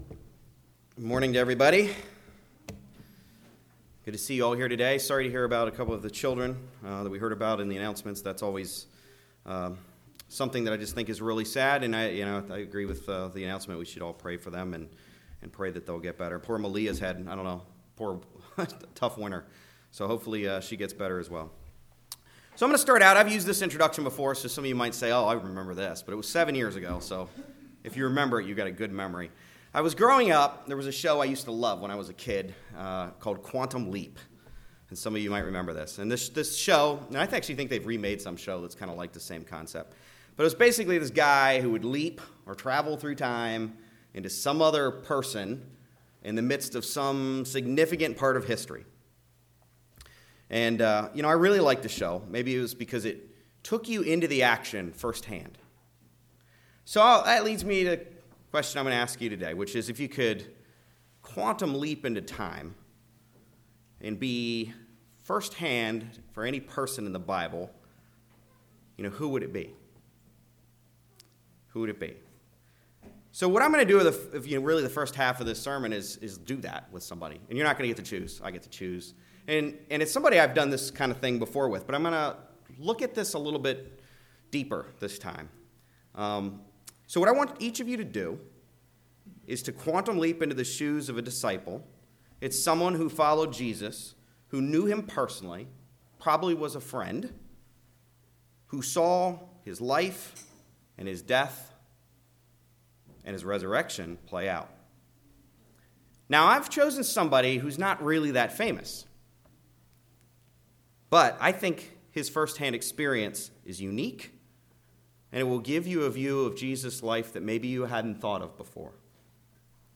Sermons
Given in Raleigh, NC Greensboro, NC